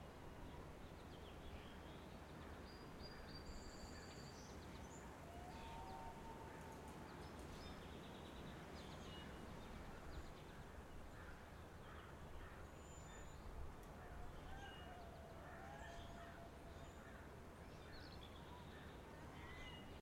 sfx_amb_map_zoomedin_plain.ogg